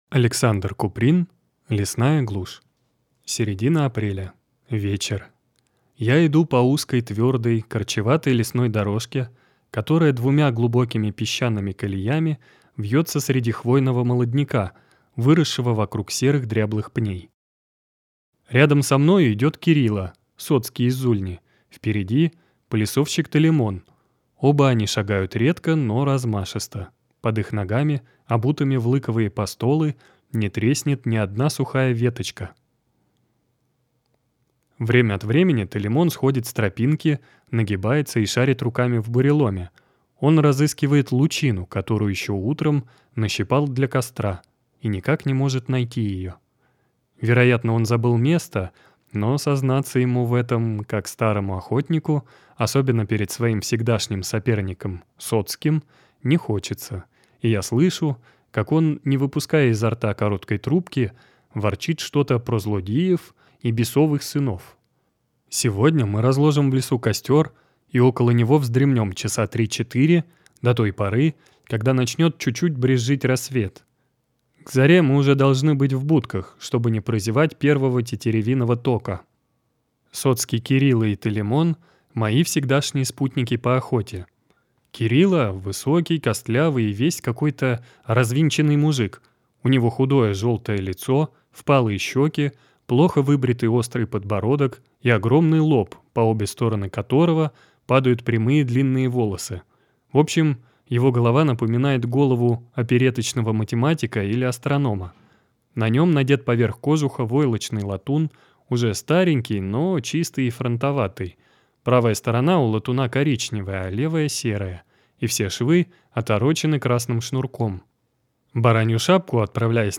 Аудиокнига Лесная глушь | Библиотека аудиокниг
Прослушать и бесплатно скачать фрагмент аудиокниги